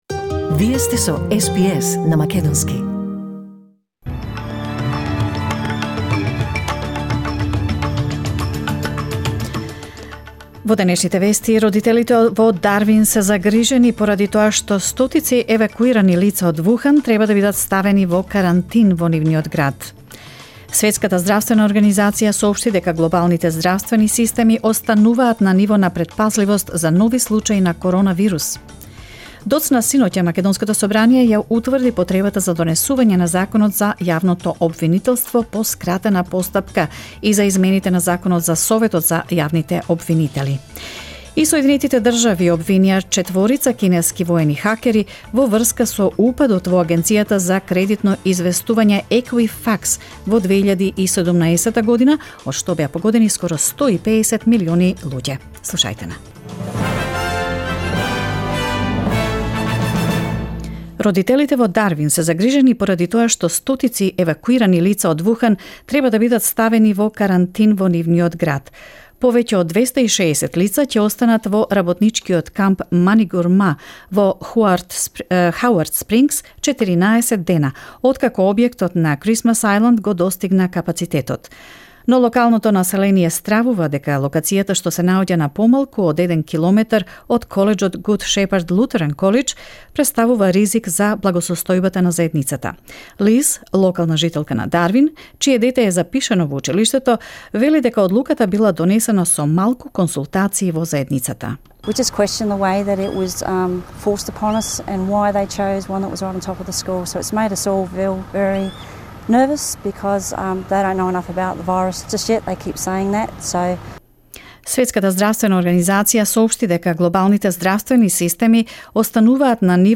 SBS News in Macedonian 11 February 2020